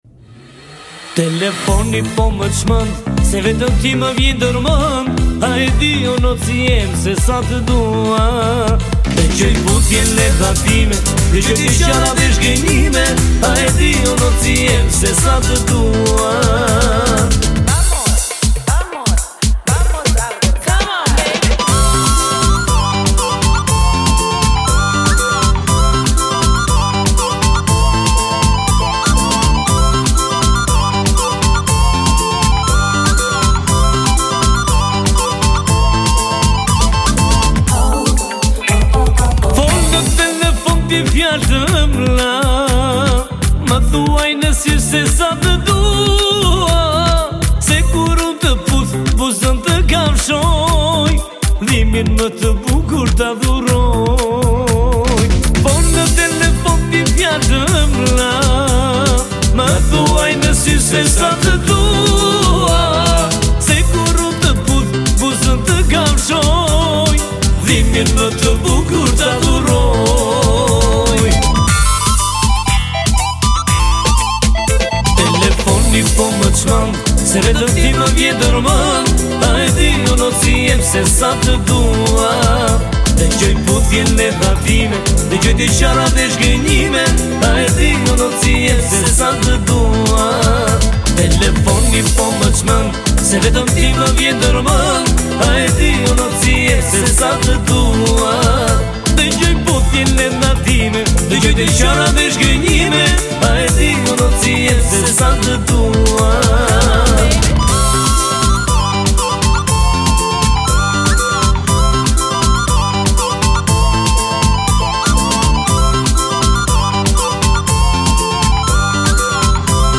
Hi. i have a home studio but i want to know how to use limiter compresion and volume to a song corectly not to be very loud.